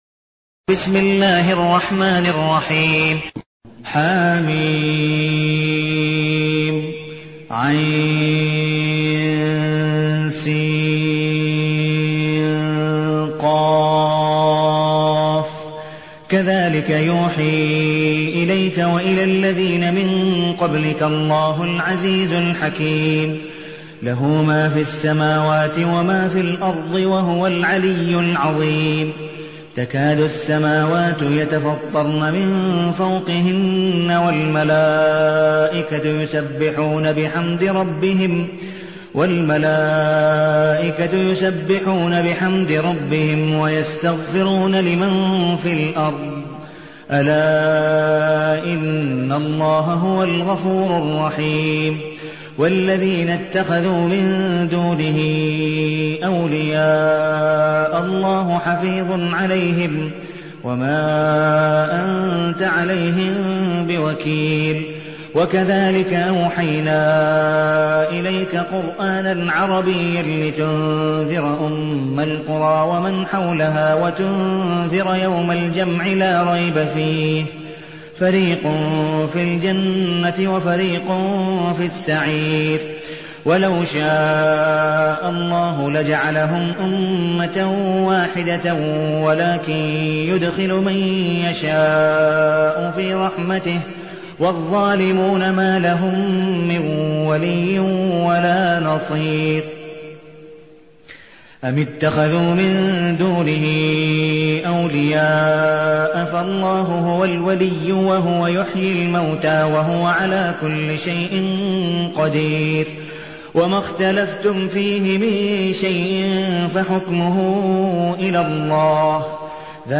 المكان: المسجد النبوي الشيخ: عبدالودود بن مقبول حنيف عبدالودود بن مقبول حنيف الشورى